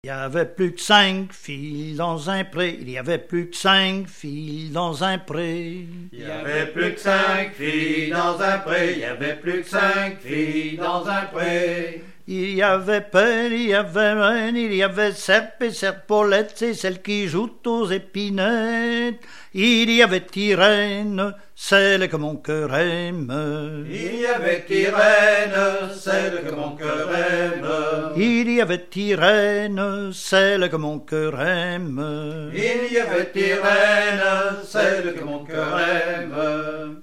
danse : ronde : grand'danse
Genre énumérative
Fonds Arexcpo en Vendée
Pièce musicale inédite